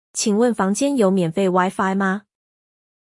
Qǐngwèn fángjiān yǒu miǎnfèi Wi-Fi ma?